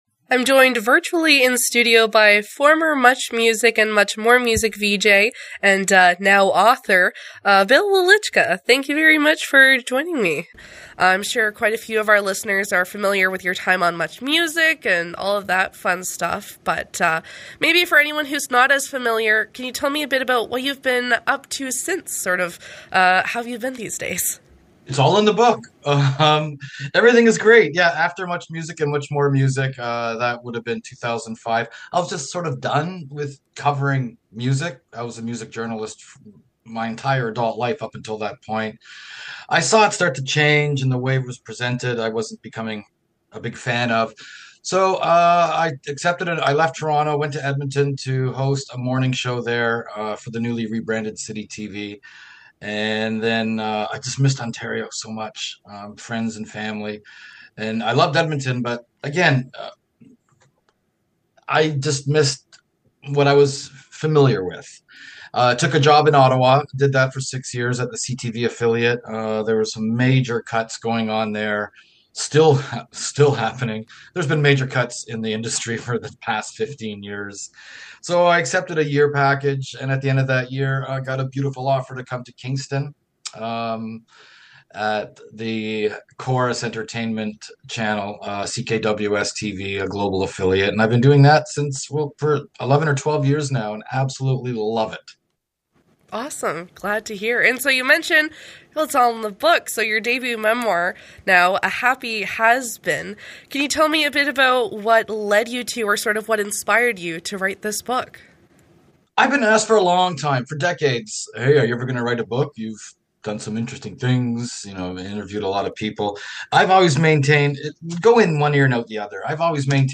bill-welychka-full-interview.mp3